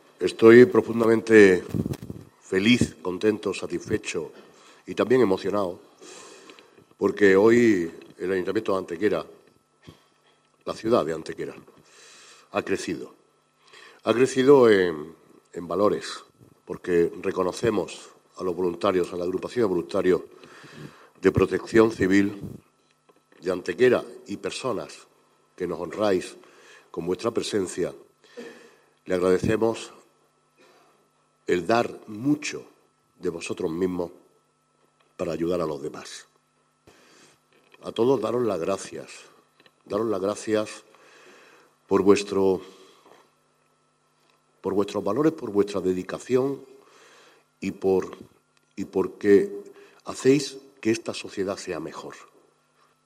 Por su parte, el alcalde Manolo Barón ha cerrado el acto felicitnado a todos los galardonados en el día de hoy, mostrando su especial satisfacción por el magnífico elenco de voluntarios que en su conjunto dispone Protección Civil en Antequera y el valioso servicio que ofrecen a nuestros vecinos en colaboración con el resto de fuerzas y cuerpos de seguridad.
Cortes de voz